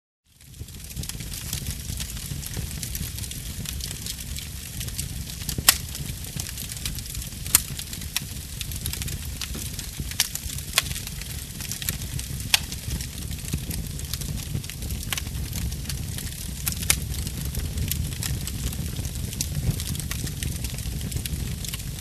Треск горящих дров в домашней печи